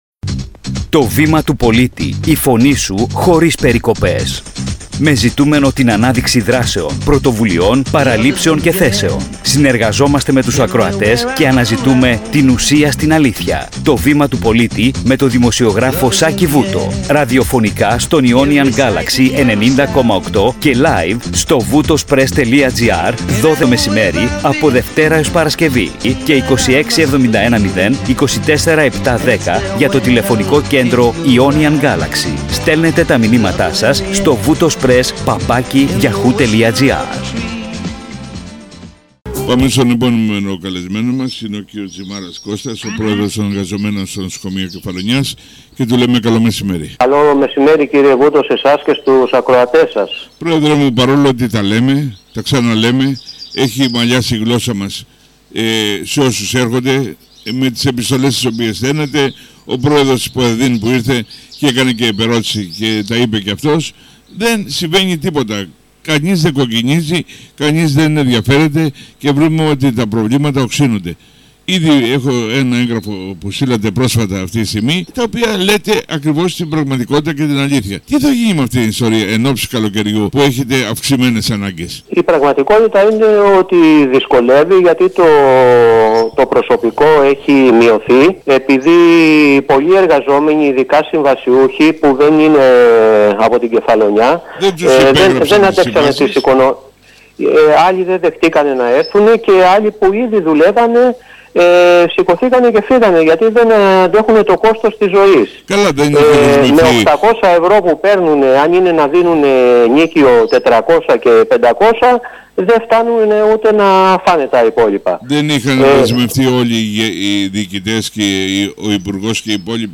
Κύρια σημεία της συνέντευξης: